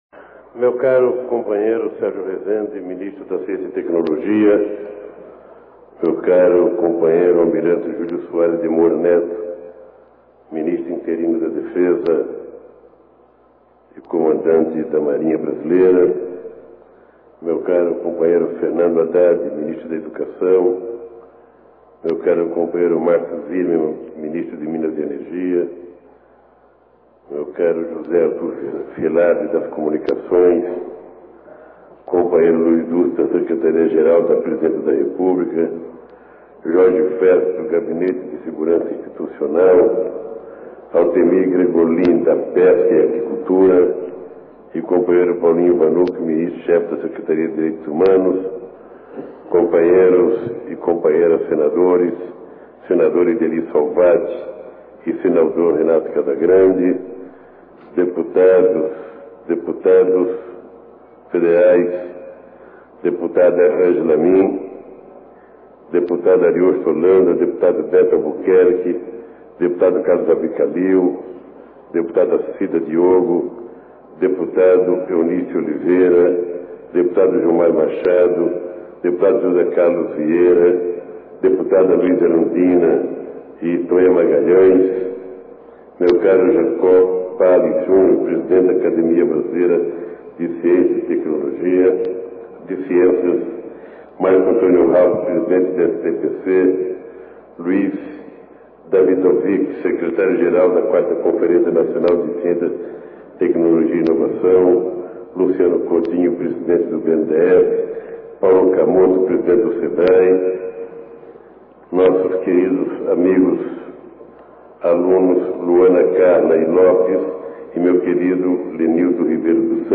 Discurso do Presidente da Rep�blica Eis a íntegra do discurso feito pelo Presidente da República, Luiz Inácio Lula da Silva, durante a solenidade de abertura da 4ª CNCTI. �udio do discurso do Presidente da Rep�blica Ouça na íntegra o discurso do Presidente da República, Luiz Inácio Lula da Silva, durante a solenidade de abertura da 4ª CNCTI.